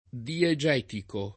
diegetico [ die J$ tiko ] agg.; pl. m. -ci